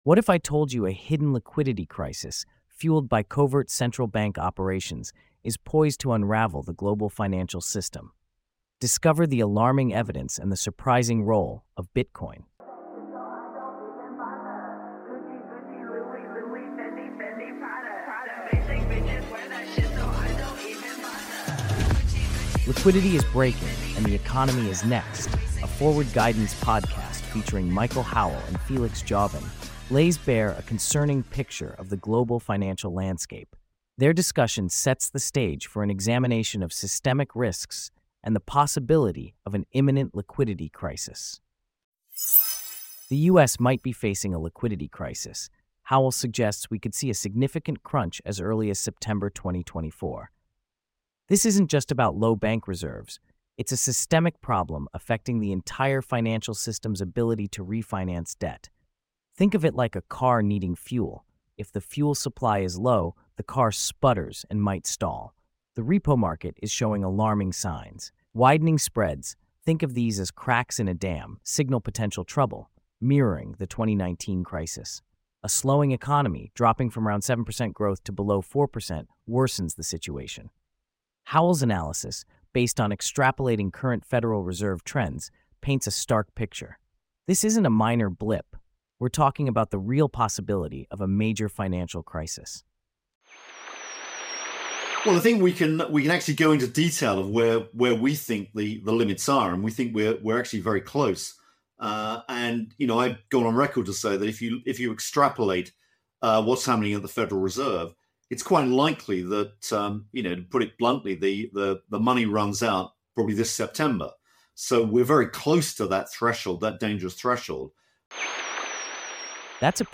Audio Summaries
Podcasts can be compressed to provide much shorter audio summaries (1 hour → 9 mins) that include excerpts from the podcast itself.